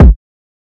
bass kick.wav